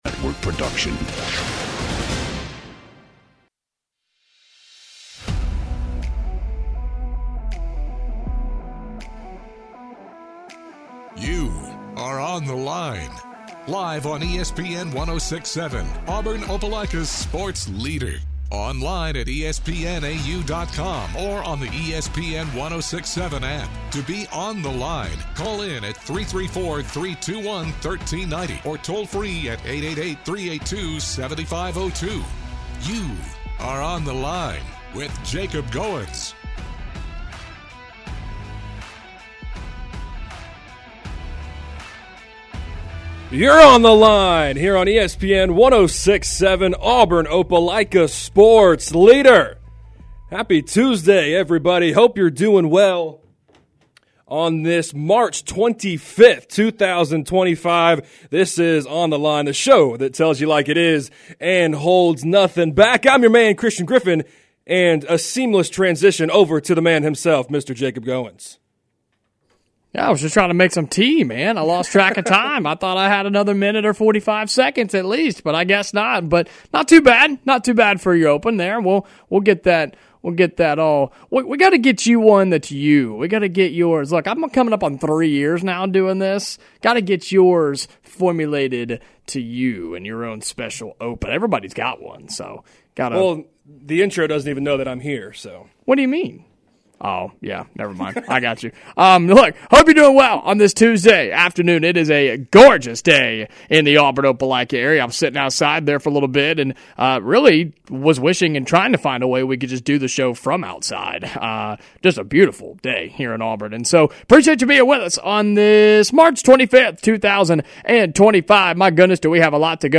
and listeners give their input as well.